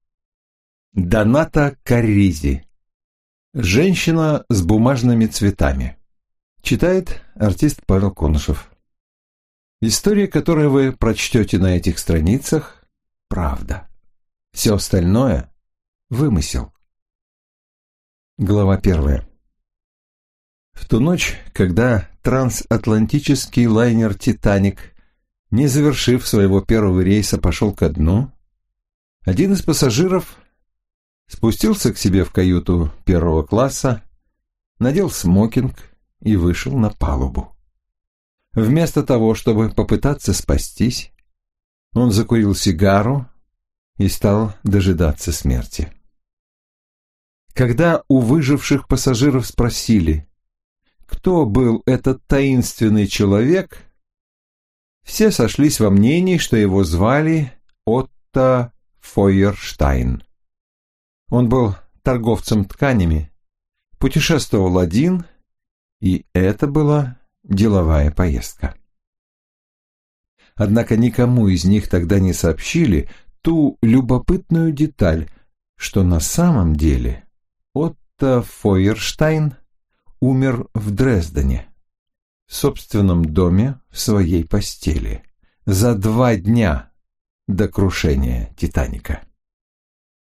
Аудиокнига Женщина с бумажными цветами | Библиотека аудиокниг